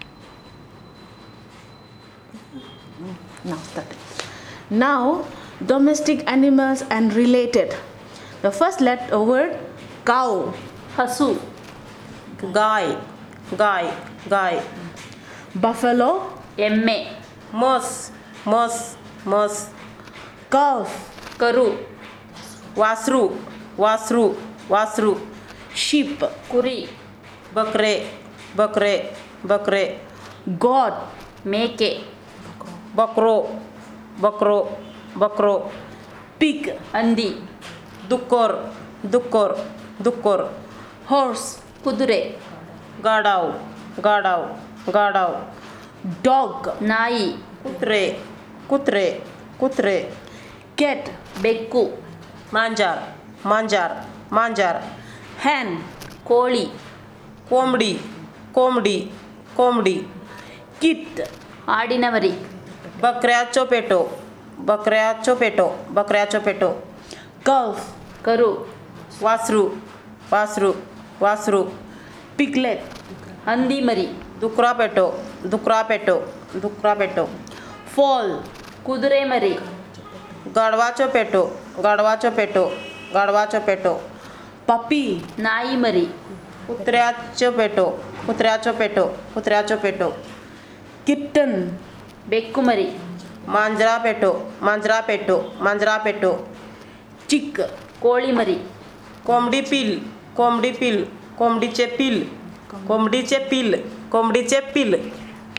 TitleElicitation of words about domestic animals and related